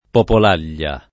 [ popol # l’l’a ]